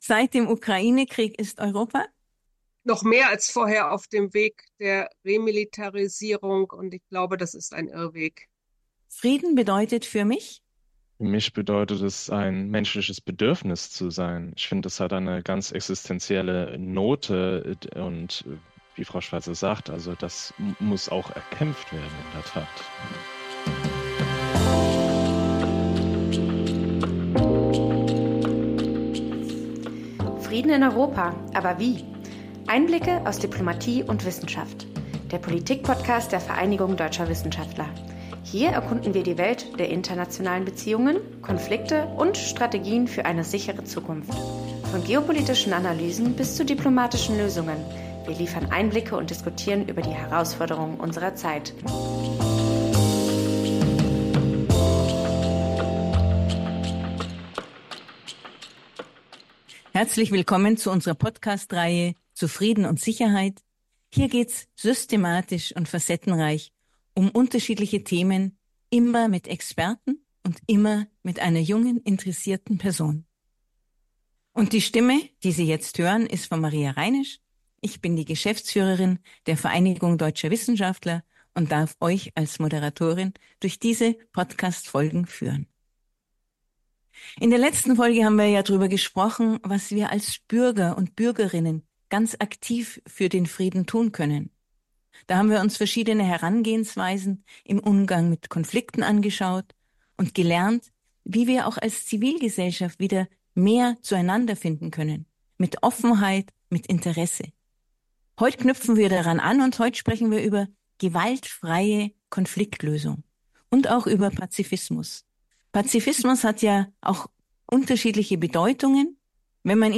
Diese Folge bietet Ihnen tiefgehende Einblicke in die praktischen und theoretischen Dimensionen der gewaltfreien Konfliktbearbeitung und zeigt, wie diese auch in der aktuellen geopolitischen Realität wirksam werden könnte. Moderiert wird das Gespräch wie gewohnt